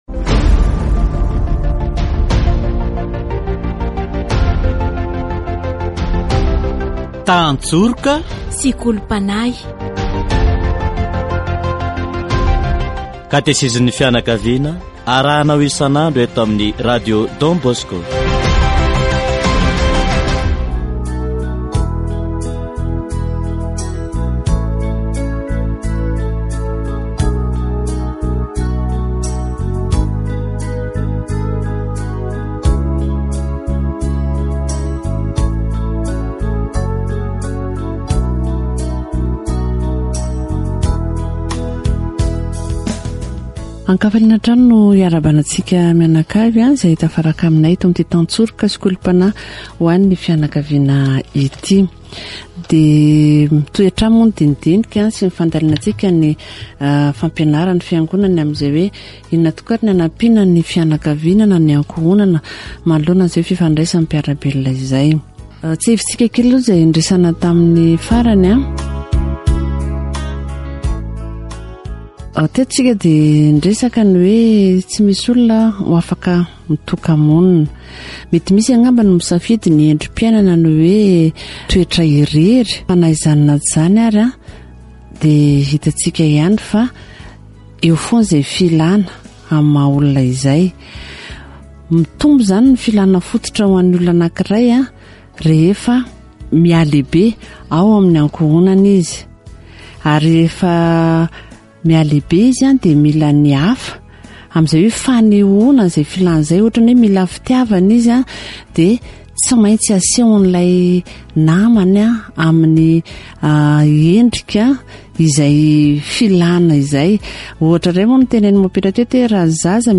Catéchèse sur la communication sociale